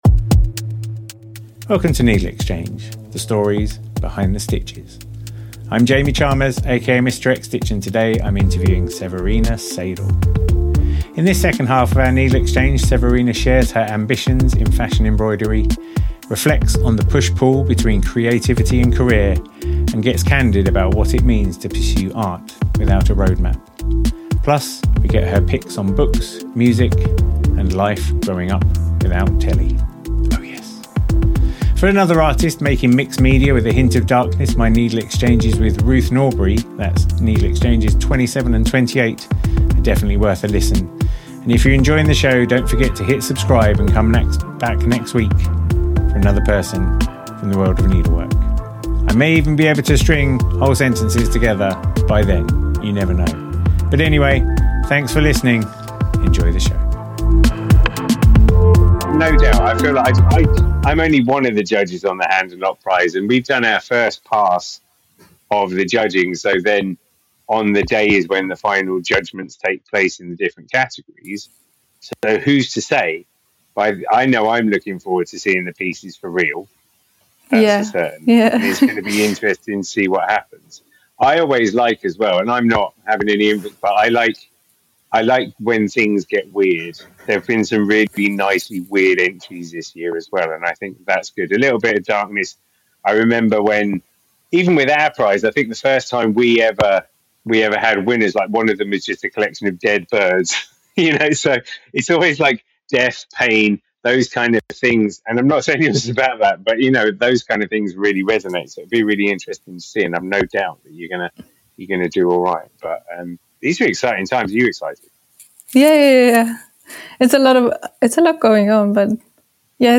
About NeedleXChange: NeedleXChange is a conversation podcast with embroidery and textile artists, exploring their process and practice.